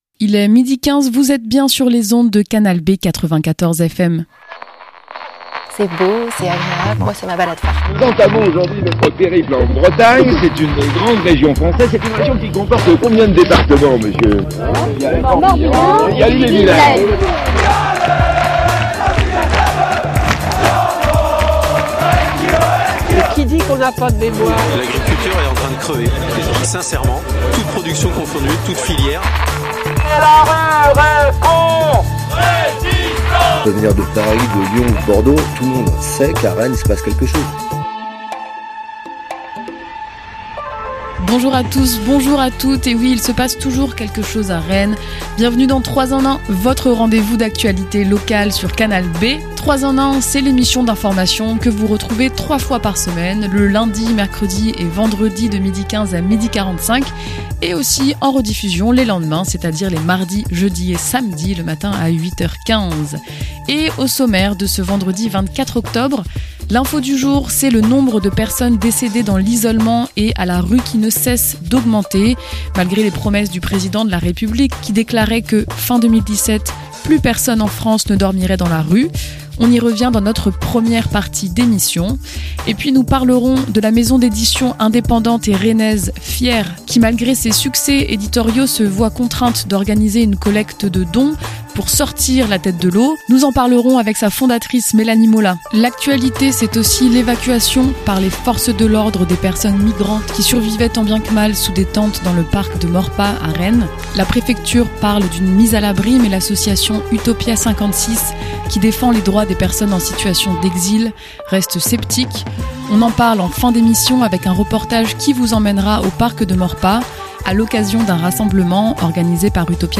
Une cagnotte pour soutenir la maison d'édition Fièr·e·s / Reportage au parc de Maurepas après l'évacuation des personnes migrantes
La radio s'est rendu au rassemblement spontané organisé par Utopia56 vendredi 23 octobre.